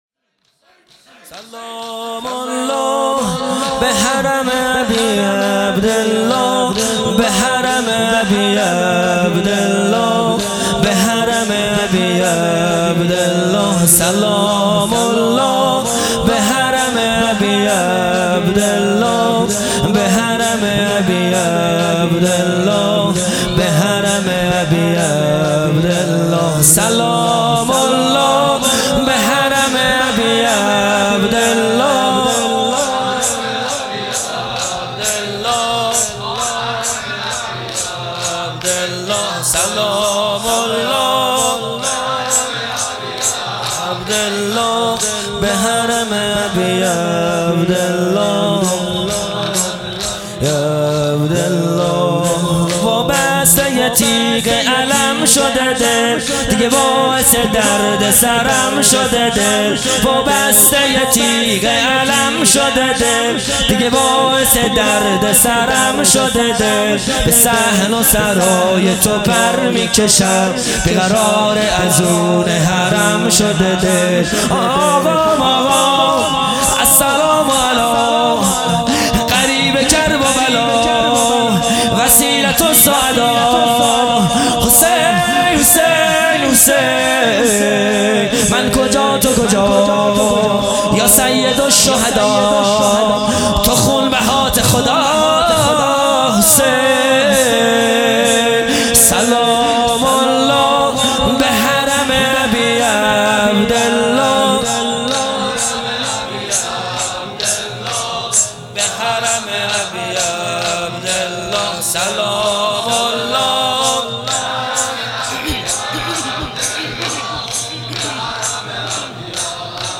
خیمه گاه - هیئت بچه های فاطمه (س) - شور دوم | سلام الله به حرم ابی عبدالله
جلسۀ هفتگی | به مناسبت شهادت حضرت رقیه